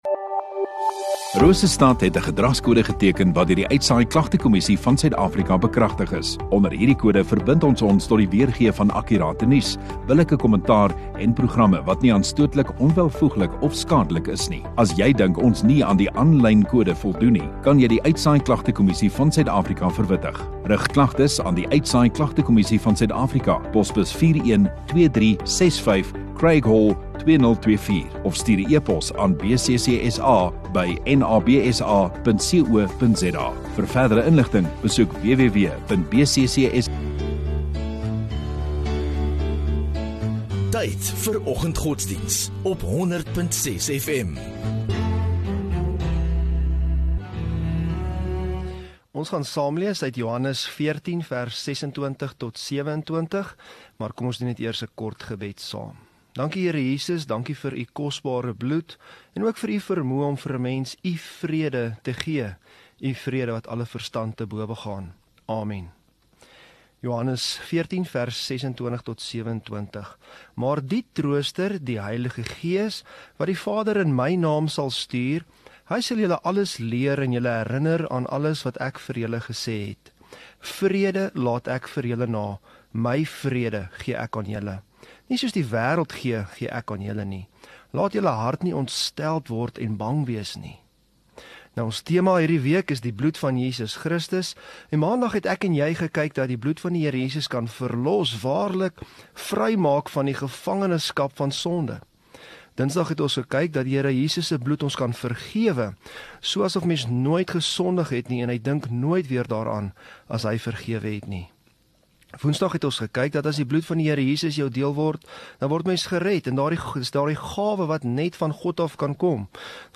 3 Oct Vrydag Oggenddiens